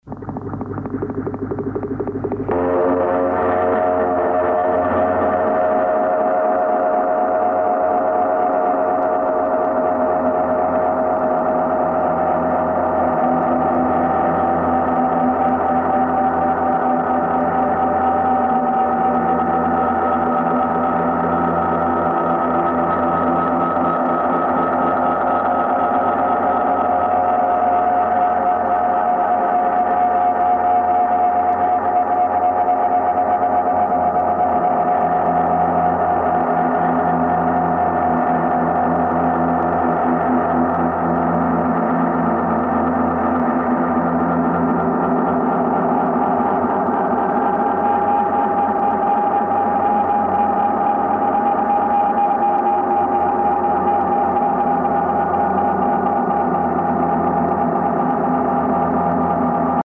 the industrial music era